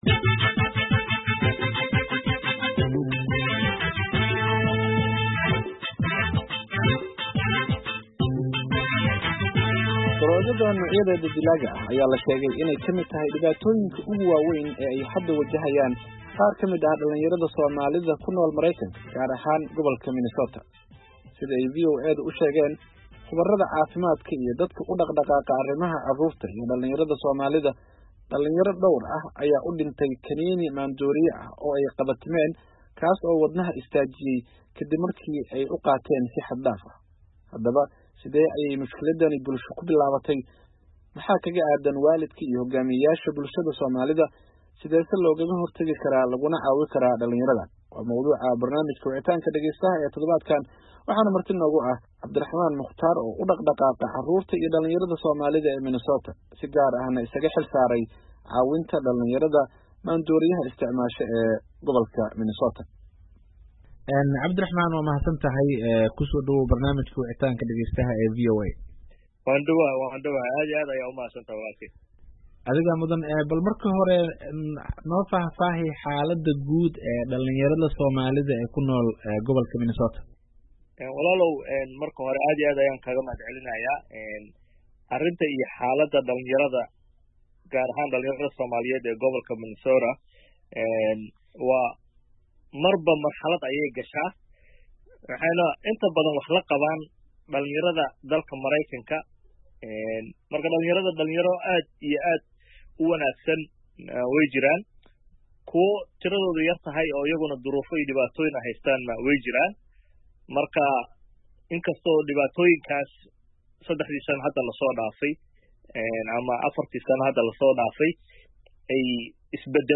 Barnaamijka Wicitaanka Dhageystaha waxa uu toddobaadkan ku saabsan yahay dhibaatada ay daroogada dilaaga ah ku hayso dhallinyarada Soomaaliyeed ee Mareykanka, gaar ahaan kuwa gobolka Minnesota, halkaasi oo dhallinyaro dhowr ah ay ugu geeriyoodeen kaniiniyaal ay cuneen.